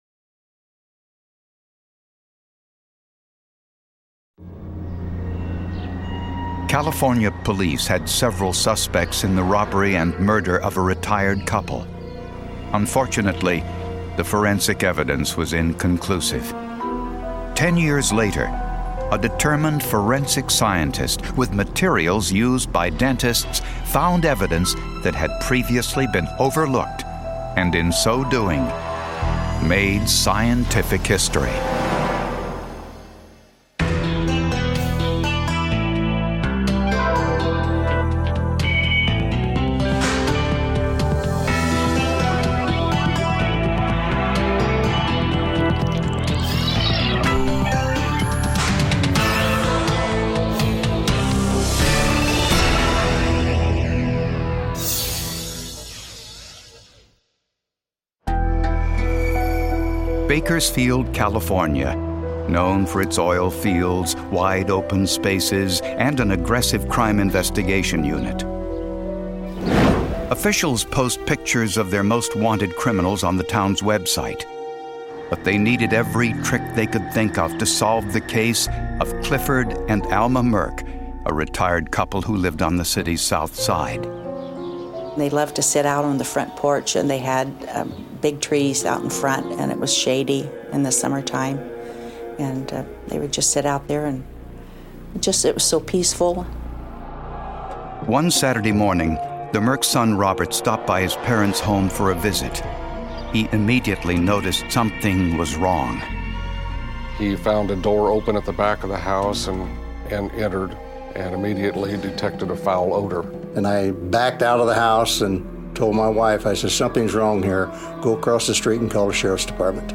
All advertisements are strategically placed only at the beginning of each episode, ensuring you can immerse yourself fully in every investigation, every revelation, and every emotional moment without advertising cuts disrupting the flow of our true crime storytelling.